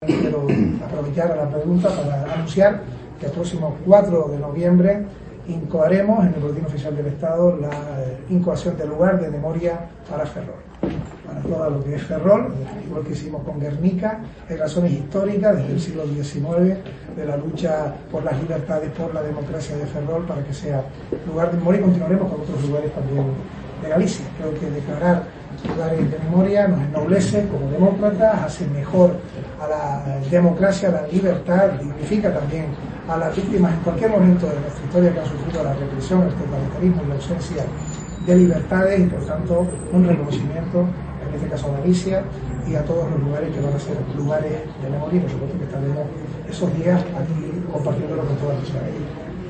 «Hay razones históricas desde el siglo XIX, de la lucha por las libertades, por la democracia, de Ferrol, para que sea Lugar de Memoria y continuaremos con otros lugares de Galicia«, ha explicado el ministro en declaraciones a los medios, tras inaugurar unas jornadas sobre controles en el tráfico internacional de mercancías.